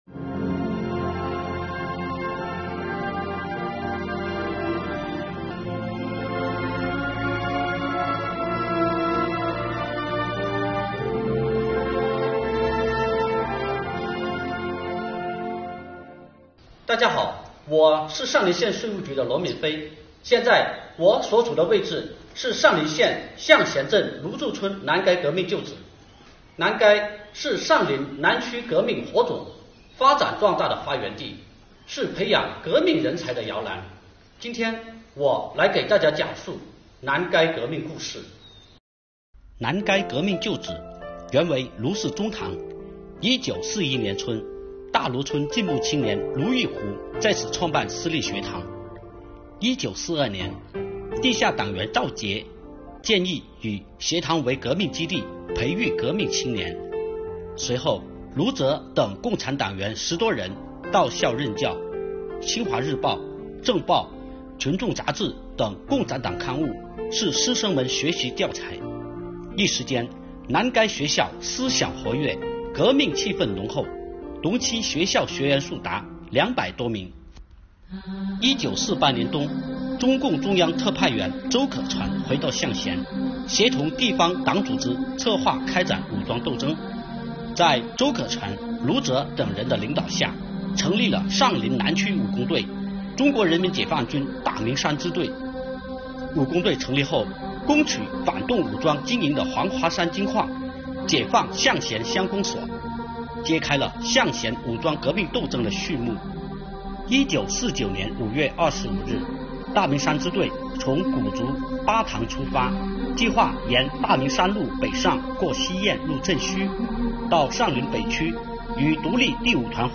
今天发布上林县税务局青年说党史之《南陔英魂永不朽》。